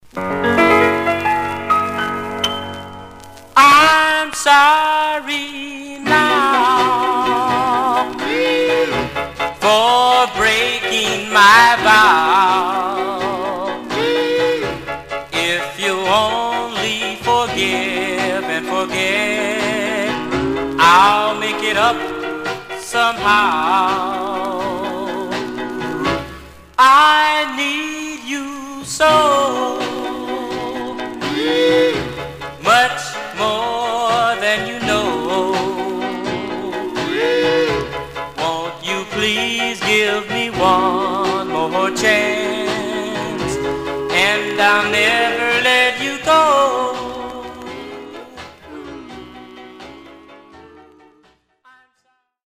Mono
Male Black Groups